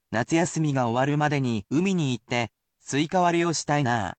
And, I will speak aloud the sentence example.